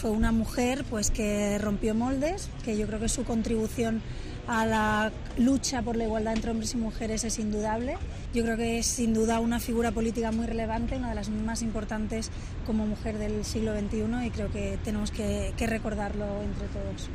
La dirigente catalana de Ciudadanos, Inés Arrimadas, ha acudido este lunes a la capilla ardiente con los restos de la exministra Carme Chacón.
Inés Arrimadas ha hecho una declaración en la que ha confesado que la imagen de Chacón, embarazada y pasando revista a las tropas como ministra de Defensa no se le va a olvidar "nunca".